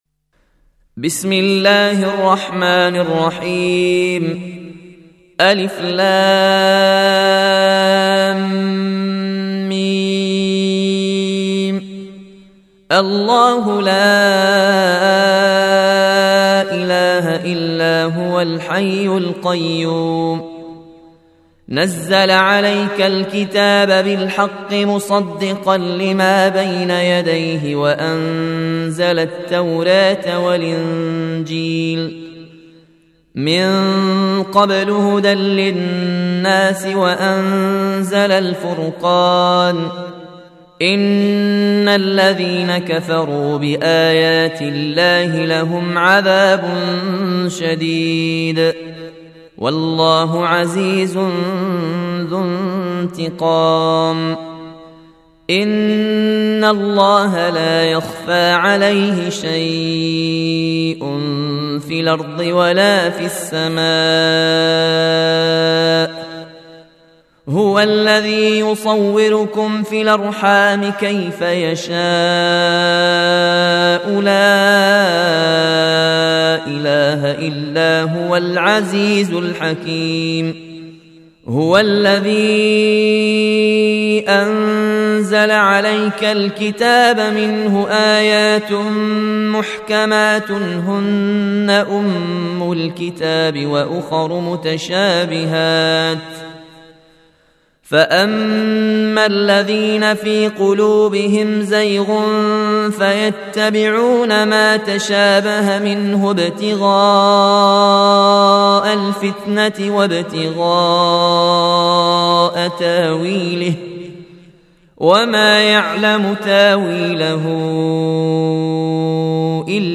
(رواية ورش)